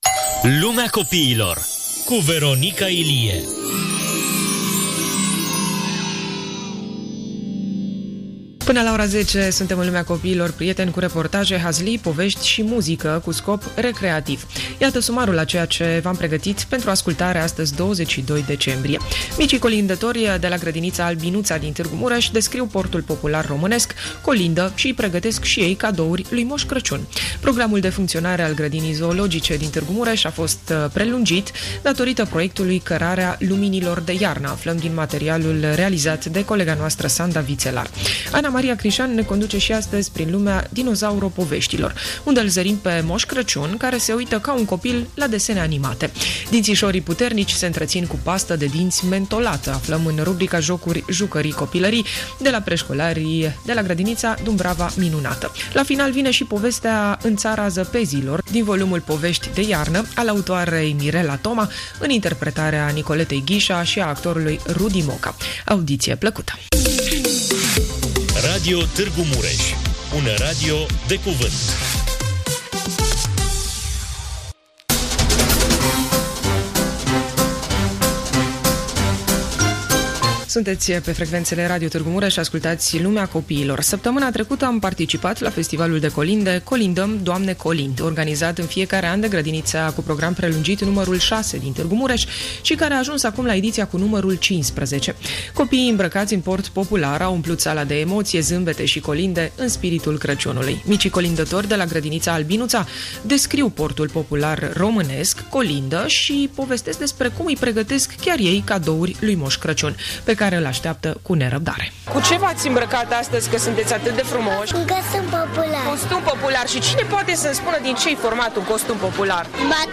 Micii colindători de la Grădinița „Albinuța” din Târgu Mureș descriu portul popular românesc, colindă și îi pregătesc cadouri lui Moș Crăciun.